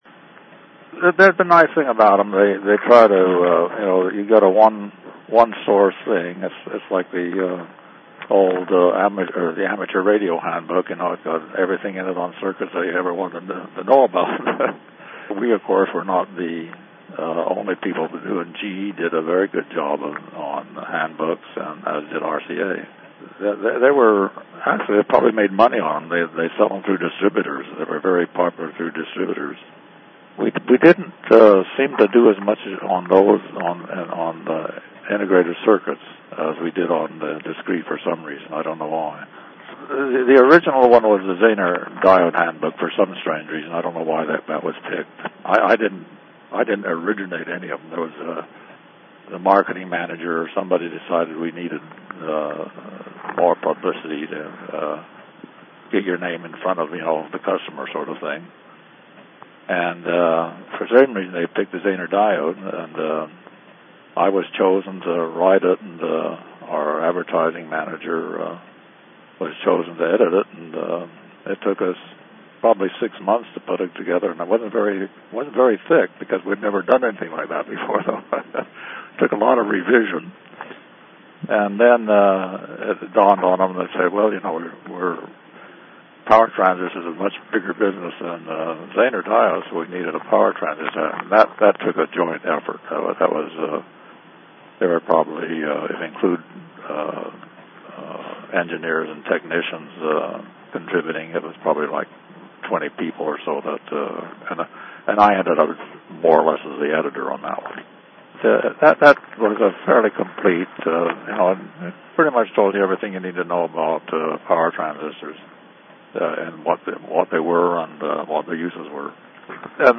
from a 2008 Interview with